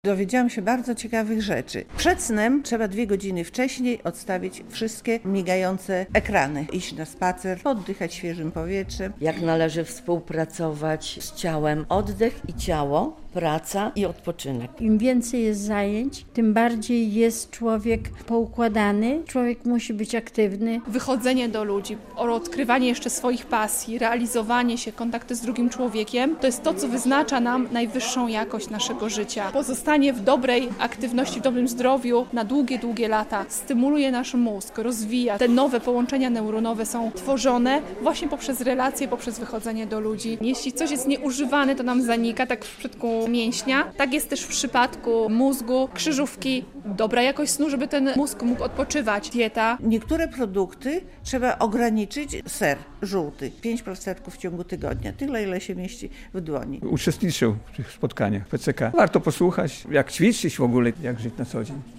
Kilkadziesiąt osób przyszło na wykład dotyczący zdrowia do Centrum Integracji Społecznej PCK w Białymstoku. Tym razem można było dowiedzieć się, jak zadbać o mózg.